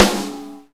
normal-hitclap2.wav